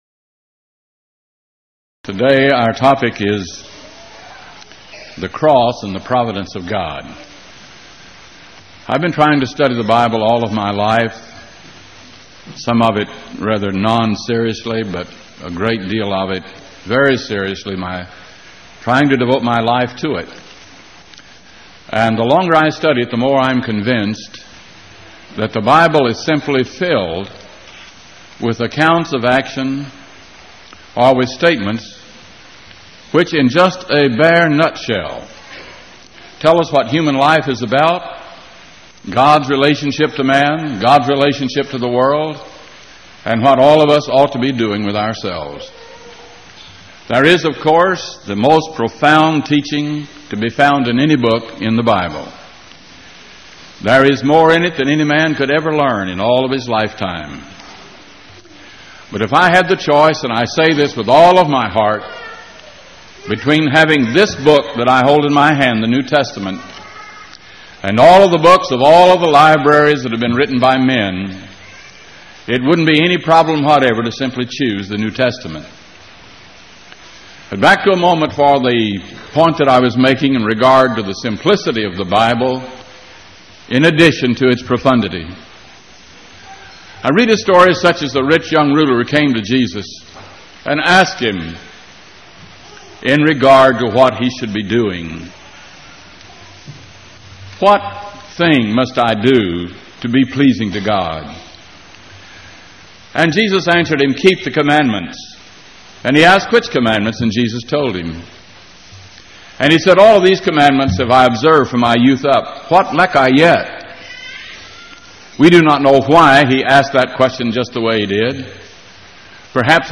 Event: 1989 Power Lectures
lecture